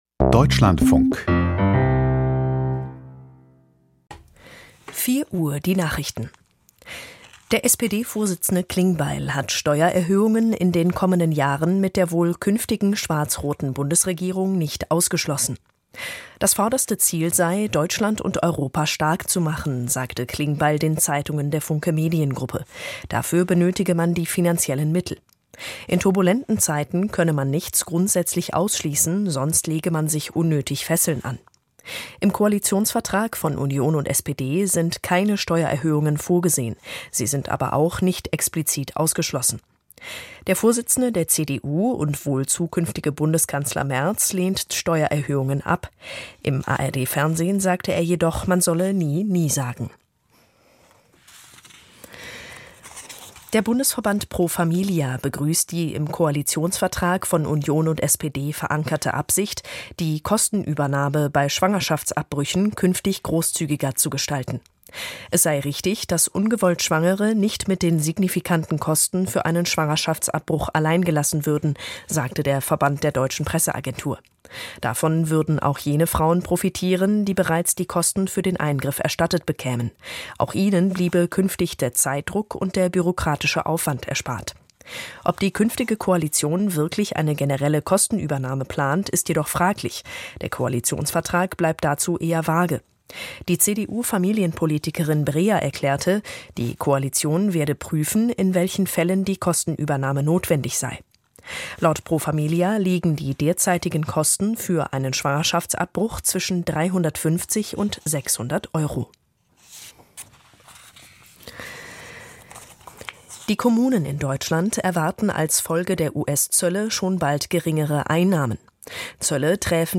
Die Deutschlandfunk-Nachrichten vom 19.04.2025, 04:00 Uhr